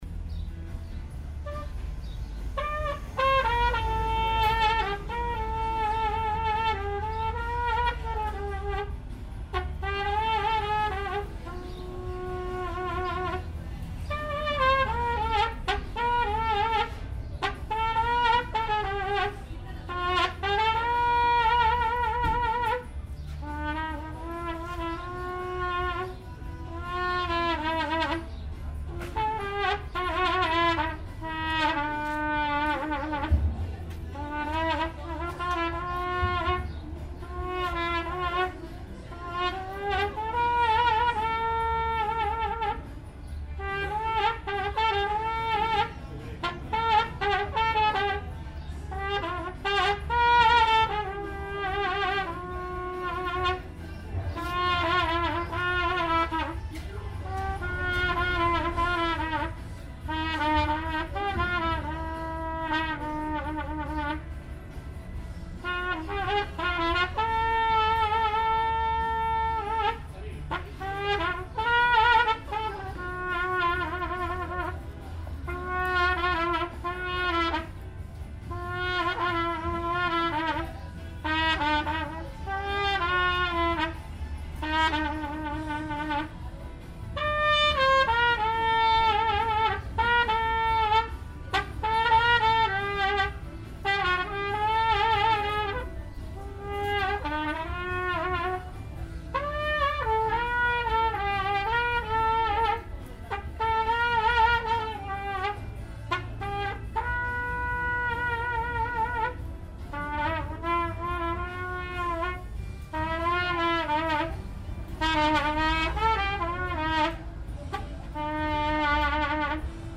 Phonography
The field recordings for this release were taken in various locations around Baja California and Mexico City using binaural microphones. They have been left unprocessed and although lack certain apparentness were not chosen arbitrarily. Varying from very concrete details to everyday situations, what they have in common is that most of the time some kind of ‘live music’ is present – either in involving musicians and instruments (linea 1, street trumpet) or just the inevitable radio playing in the background (red glass, a hole in the day).